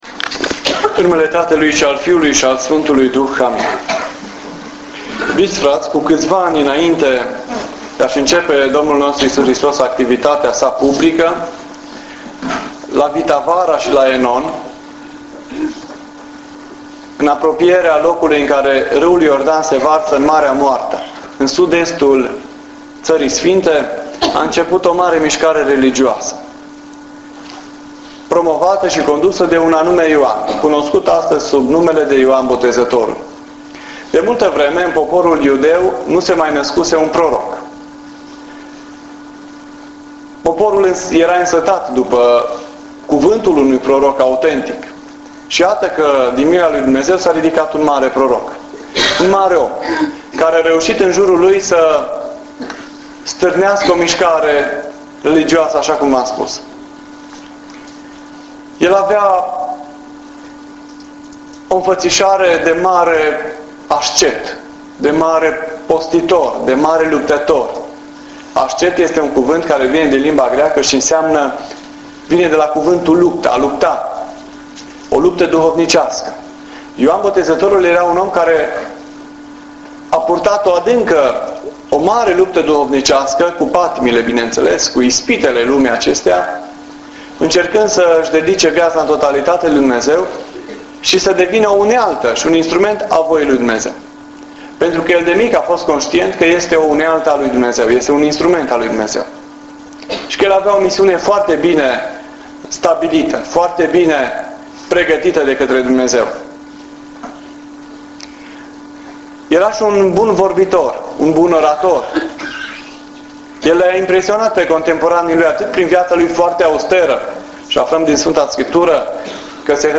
Cuvânt la Botezul Domnului – 2010